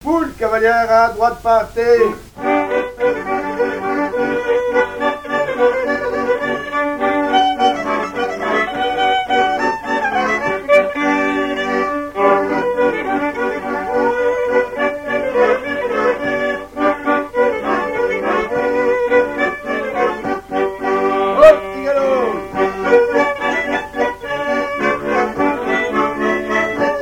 danse : quadrille : poule
Pièce musicale inédite